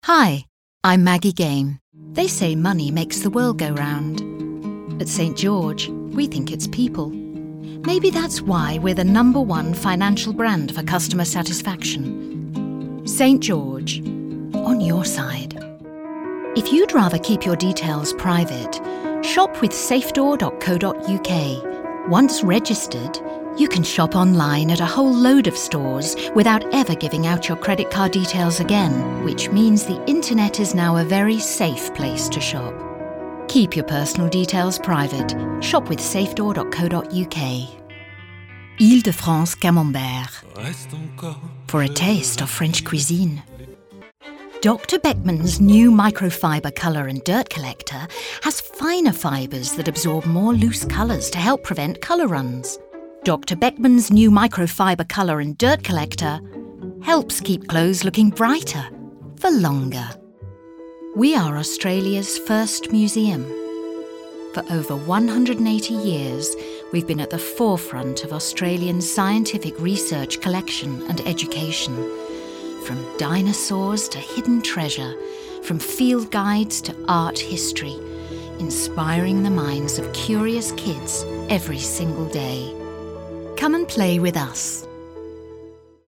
Adult (30-50) | Older Sound (50+)
1201Voice_Reel_Commercials.mp3